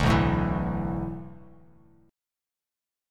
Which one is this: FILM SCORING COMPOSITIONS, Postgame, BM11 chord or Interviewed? BM11 chord